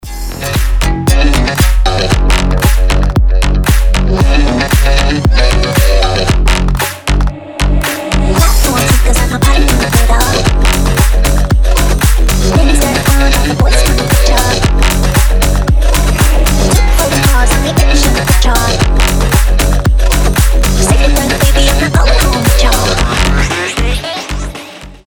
мощные басы
качающие
house
ремиксы